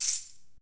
shaker.ogg